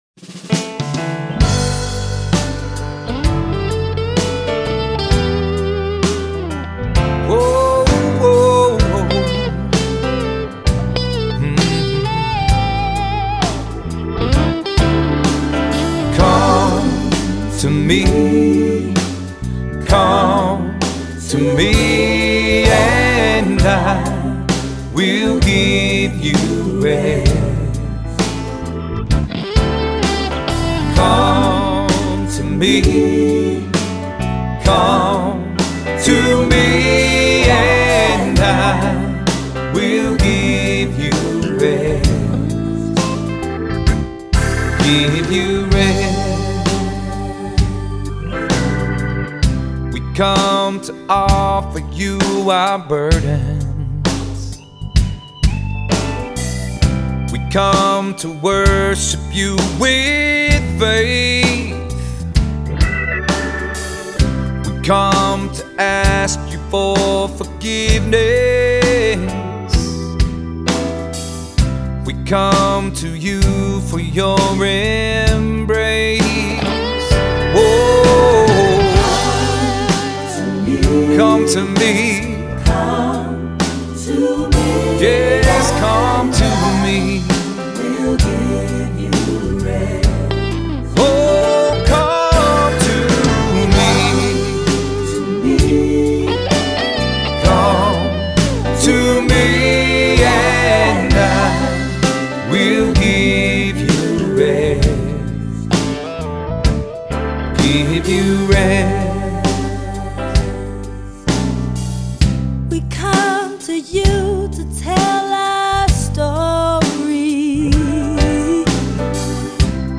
Voicing: Three-part equal; Cantor; Assembly